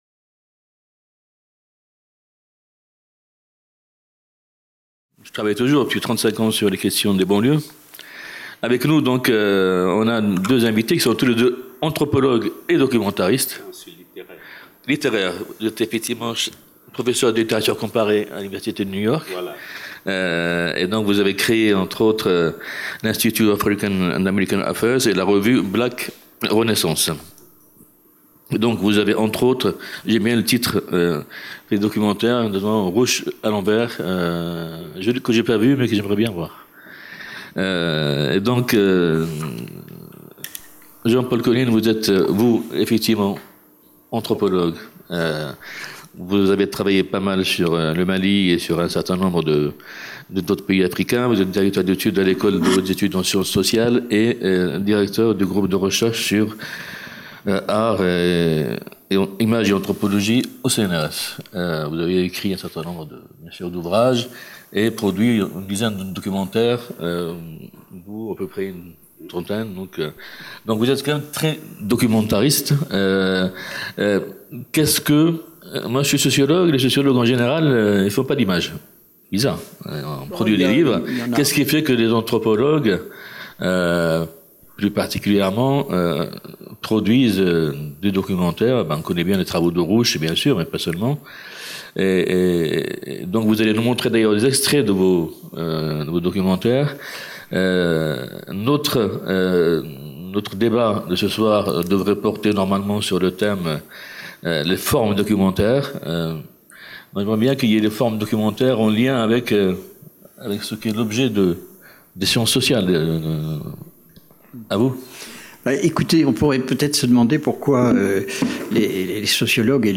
Nouveaux regards DEBAT
Jeudi 19 mai - Philharmonie, salle de conférence 17h10 : Nouveaux lieux / Nouveaux regards.